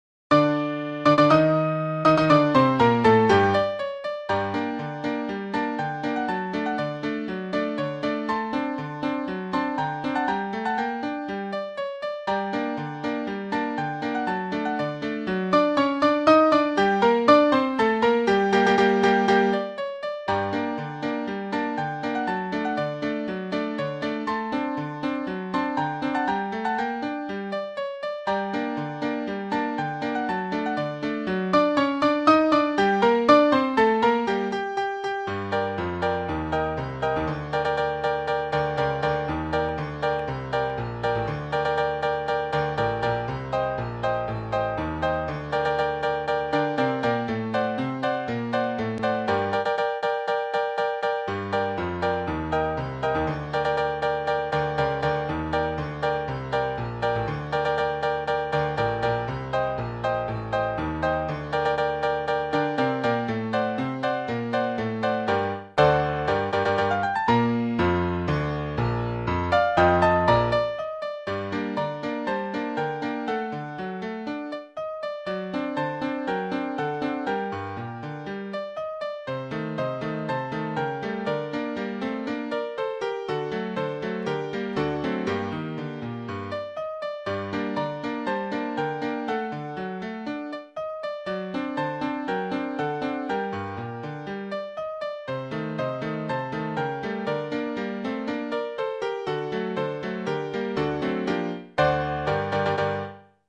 Воспроизведено по нотам с помощью программы MagicScore Maestro:
«Сочинение Д. М. Трифонова (оригинал)» — согласно его нотному изданию